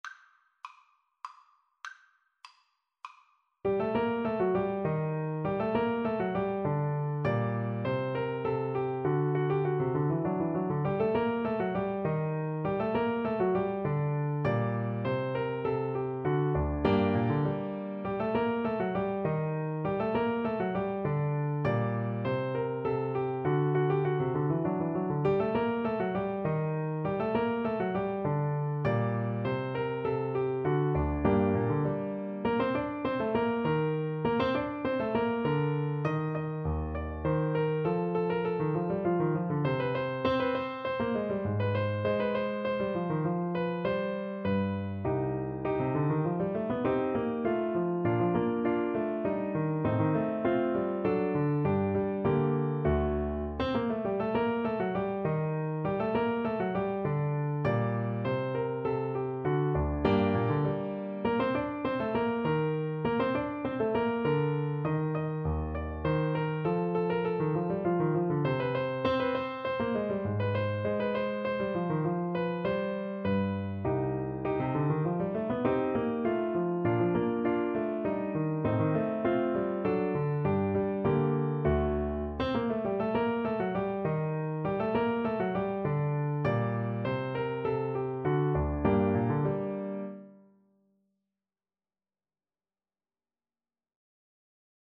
3/4 (View more 3/4 Music)
Allegretto = 100
Classical (View more Classical Flute Music)